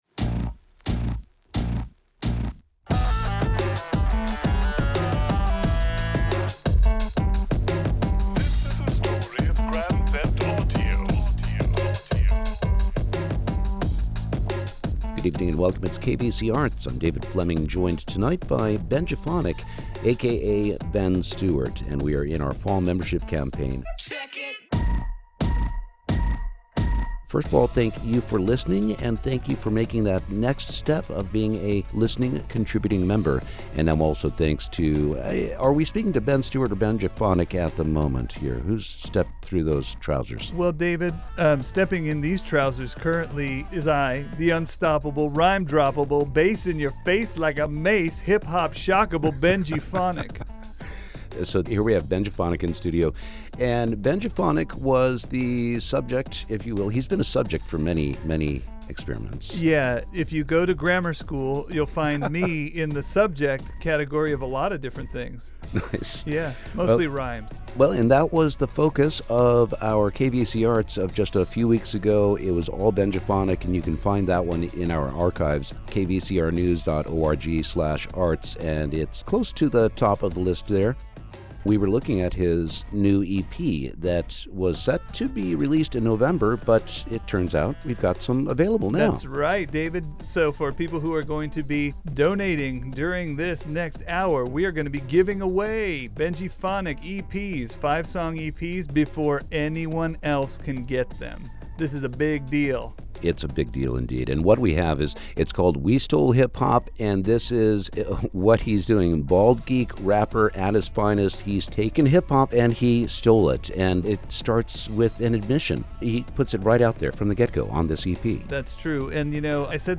Interviews with authors, producers, visual artists and musicians, spotlighting a classic album or a brand new release.